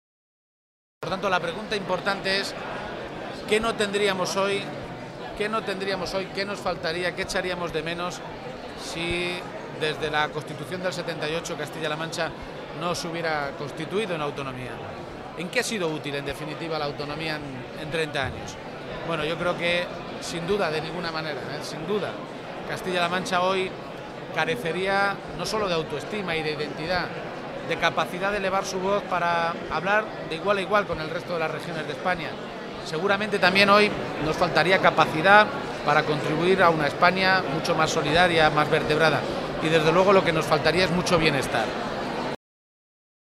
Se pronunciaba de esta manera García-Page a preguntas de los medios de comunicación, justo a su llegada al Auditoria de Cuenca, lugar elegido para celebrar este año el Día de Castilla-La Mancha, que coincide con el trigésimo aniversario de las primeras Cortes autonómicas y democráticas.
Cortes de audio de la rueda de prensa